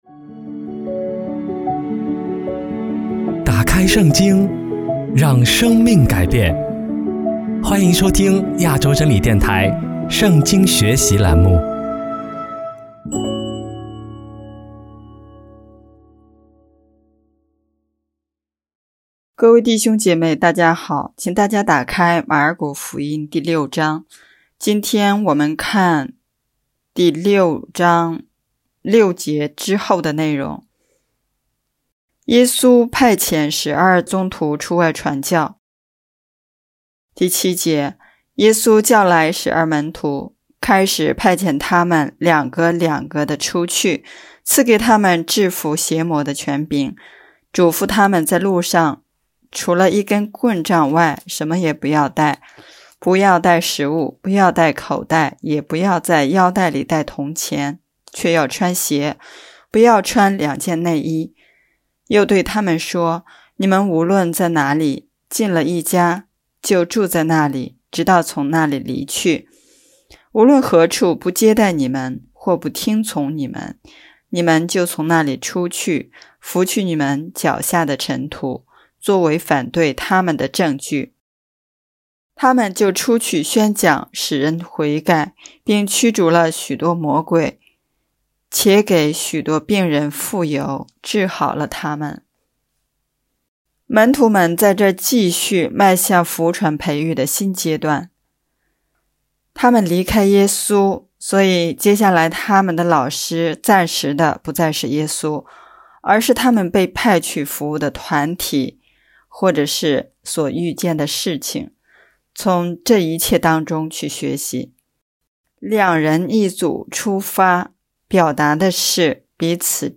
【圣经课程】|马尔谷福音第六讲